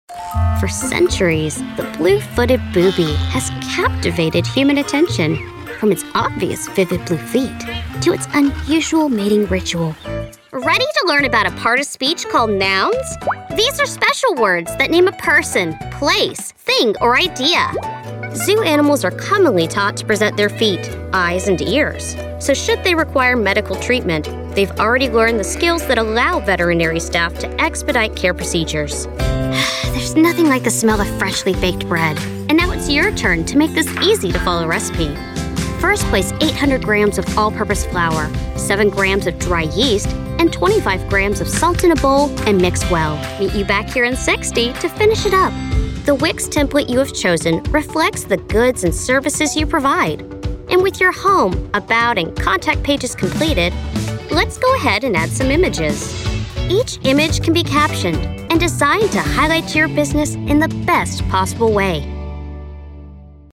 Narration
My voice has been described as sassy and quirky, but I can still be warm and comforting.